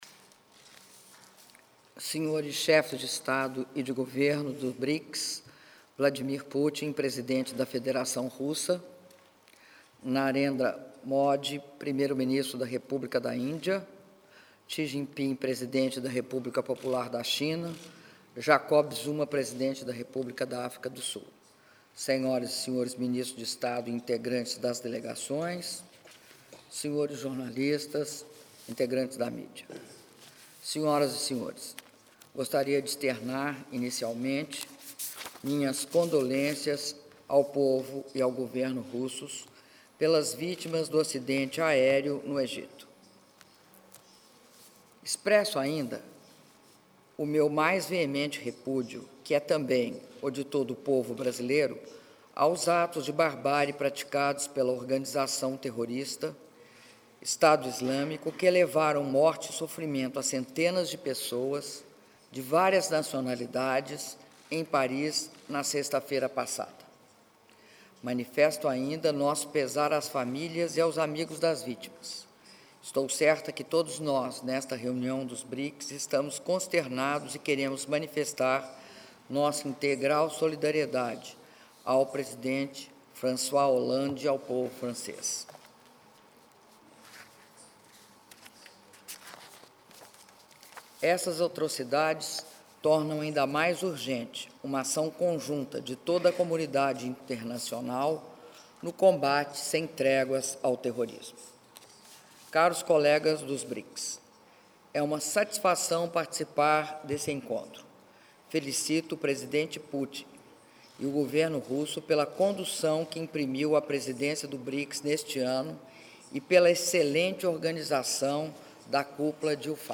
Áudio do discurso da presidenta da República, Dilma Rousseff, durante reunião de Líderes dos Brics - Antália/Turquia (03min58s)